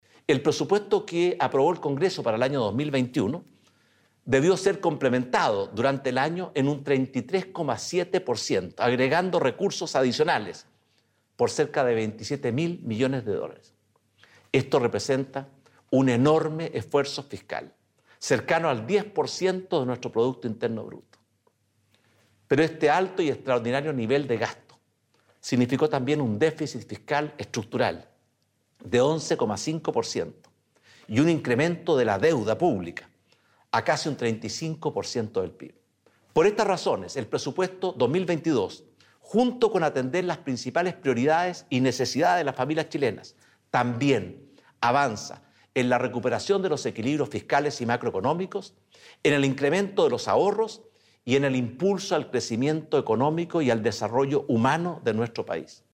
A través de una cadena nacional, el Presidente Sebastián Piñera, sostuvo que este presupuesto “representa un gran paso adelante en la recuperación de los equilibrios fiscales, la estabilización de la deuda pública que terminará este año en 34,4% del PIB y el fortalecimiento de los pilares del crecimiento y desarrollo de nuestro país”.